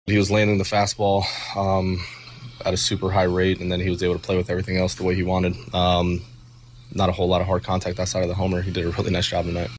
Cardinals manager Oliver Marmol talks about starting pitcher Sonny Gray’s outing.